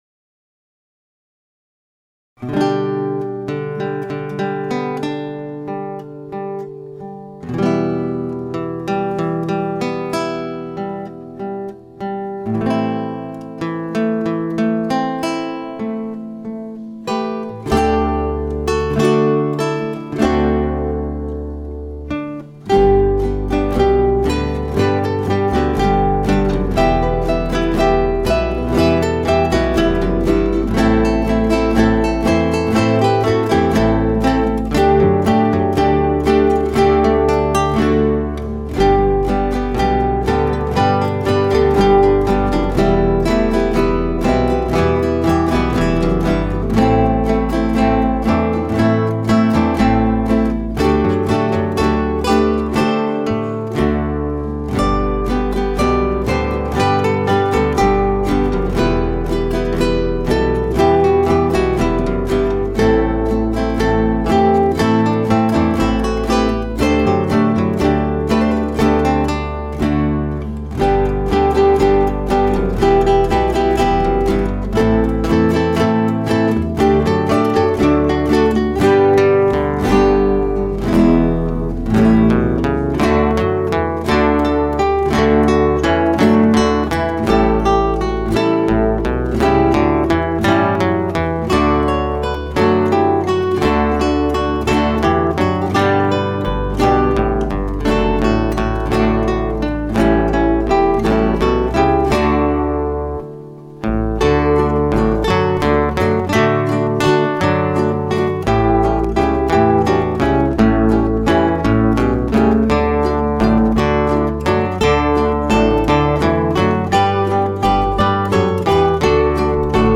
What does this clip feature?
Gitarre, akustische Gitarre